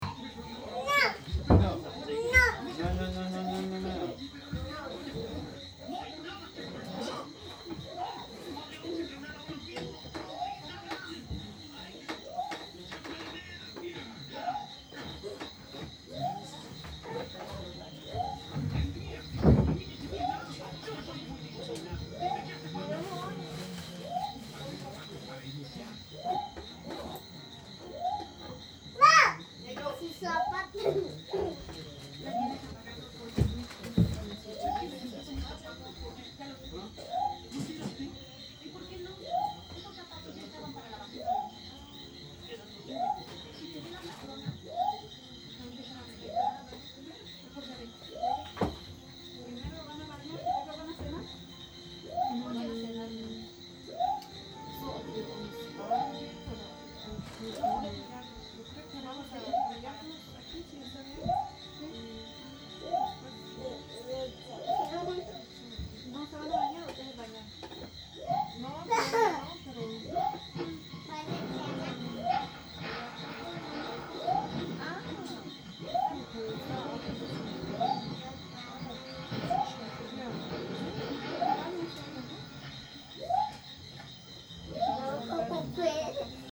Emmitouflées dans ce qu'on peut trouver de sec, nous regardons un film avec les enfants, accompagnées par le chant des grenouille.